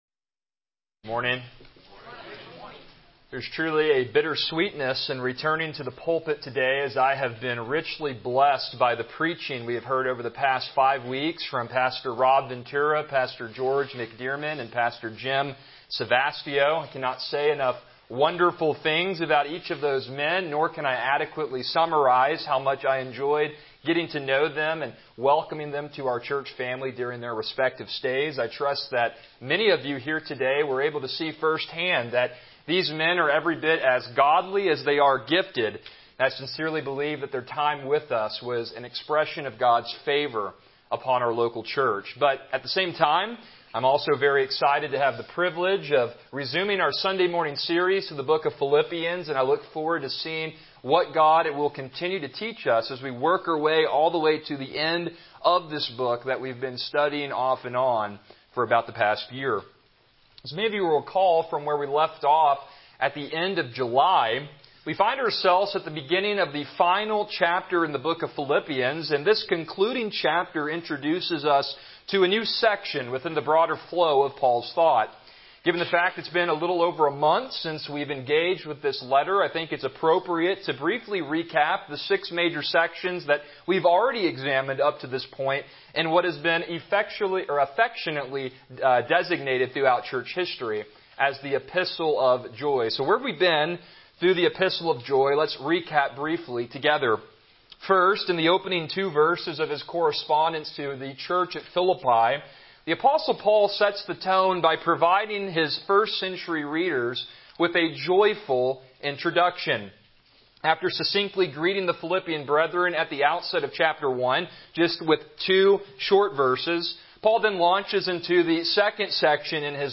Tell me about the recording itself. Passage: Philippians 4:1-3 Service Type: Morning Worship